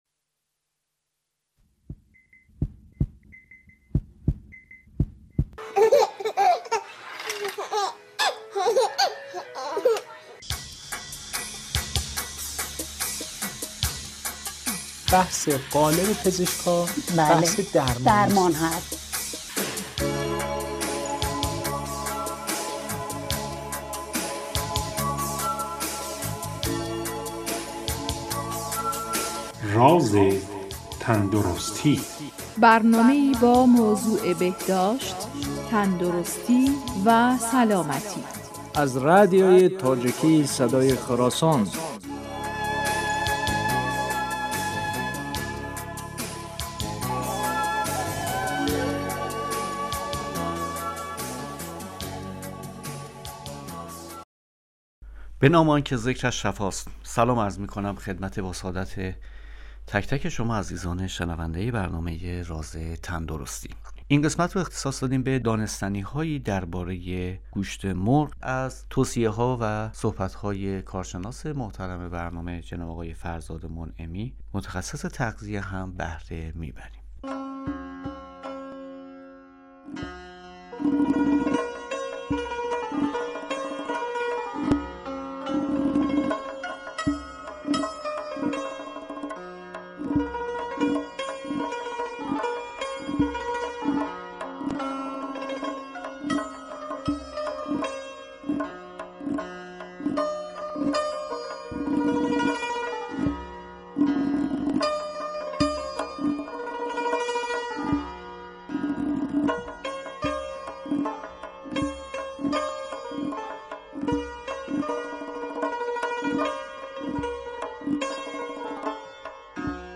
برنامه " راز تندرستی" با هدف ارائه الگوی صحیح تندرستی برای فرد و به تبع آن داشتن جامعه سالم در رادیو تاجیکی صدای خراسان تهیه و پخش می شود. توجه به سلامت جسمی و روحی خانواده ها موضوع این سلسله برنامه هاست.